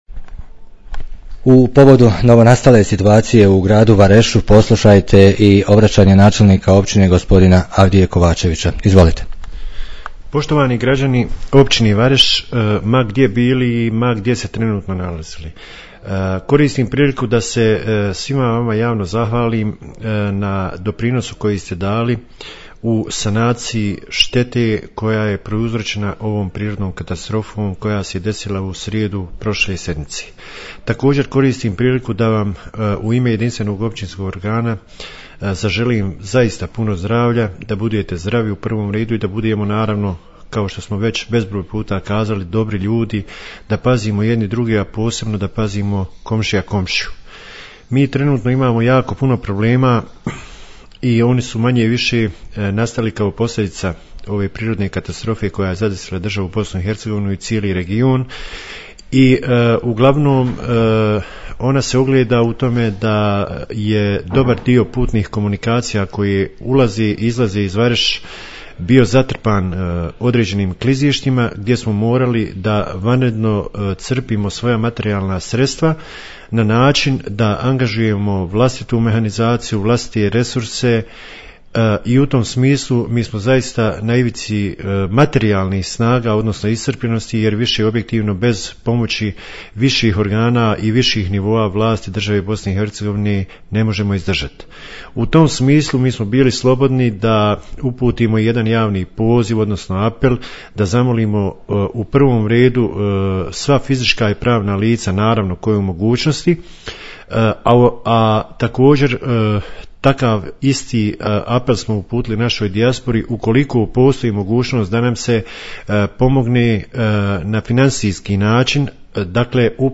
Obraćanje načelnika građanima i dijaspori
U povodu novonastale situacije u gradu poslušajte obraćanje načelnika općine Avdije Kovačevića...